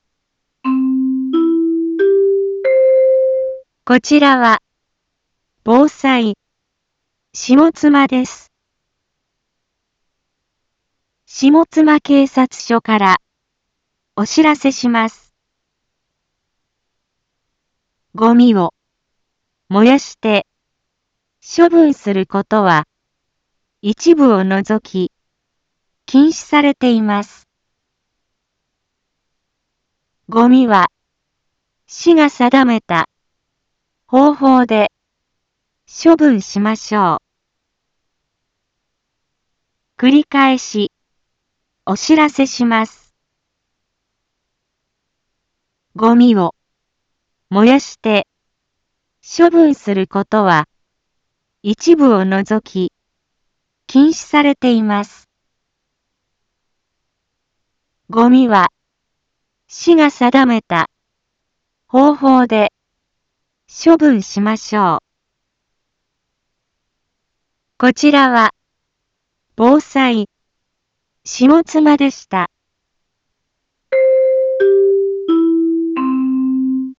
一般放送情報
Back Home 一般放送情報 音声放送 再生 一般放送情報 登録日時：2022-05-25 10:01:21 タイトル：ごみの野焼禁止 インフォメーション：こちらは、防災、下妻です。